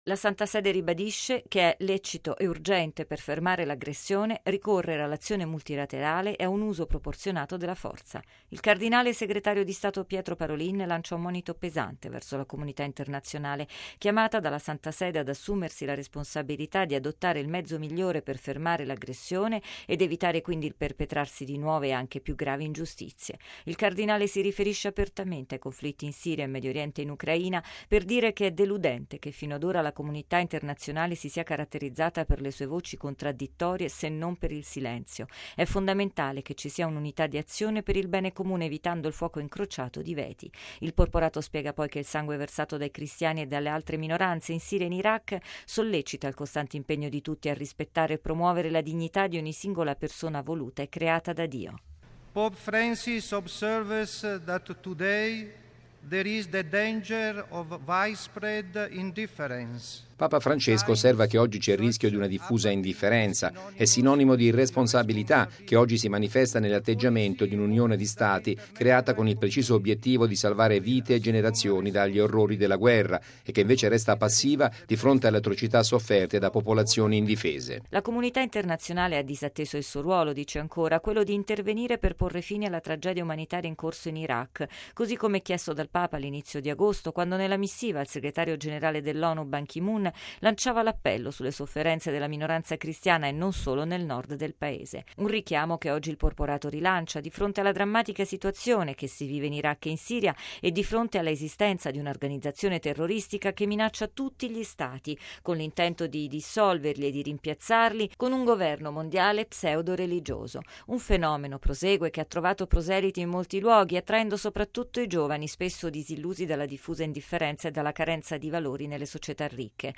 Così ieri alla 69.ma sessione della Assemblea generale delle Nazioni Unite a New York il segretario di Stato vaticano, il cardinale Pietro Parolin, è intervenuto sulle crisi in atto nel mondo. Centrale nel suo intervento anche la persecuzione nei confronti dei cristiani e delle minoranze.